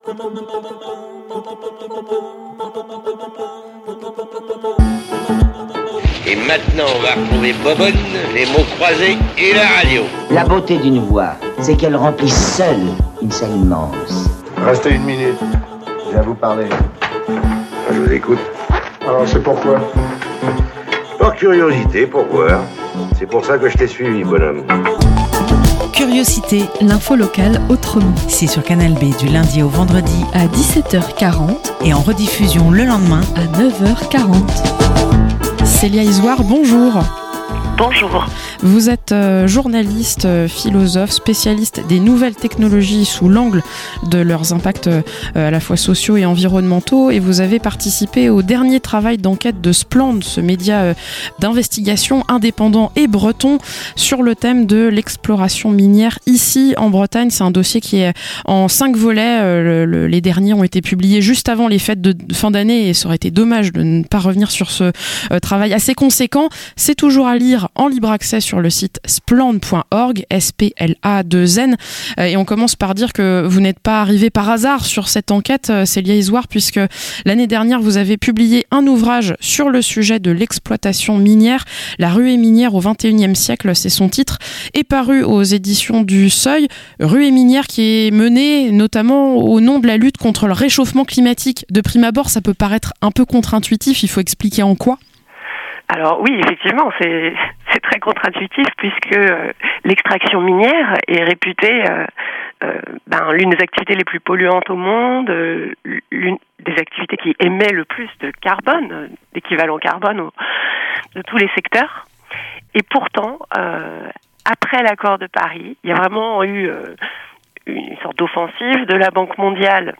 - Interview